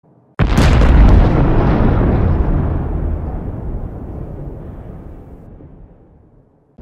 Звуки дирижабля
Грохот взрыва дирижабля